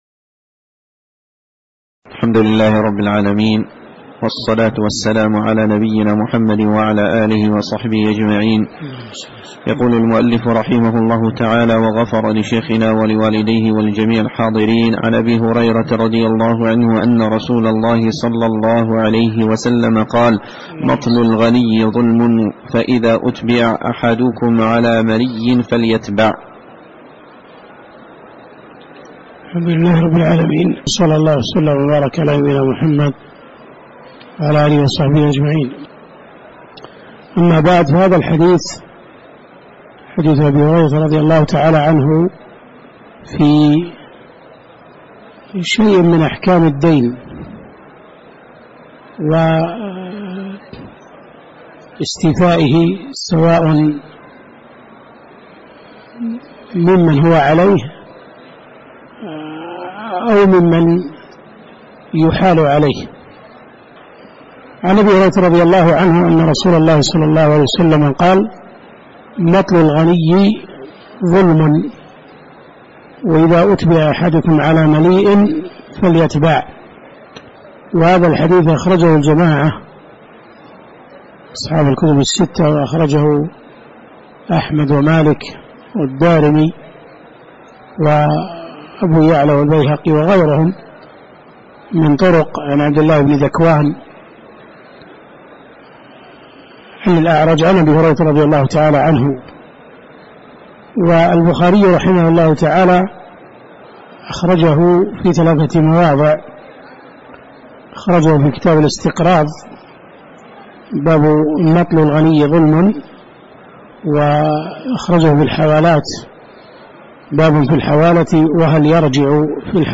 تاريخ النشر ١٥ ربيع الأول ١٤٣٩ هـ المكان: المسجد النبوي الشيخ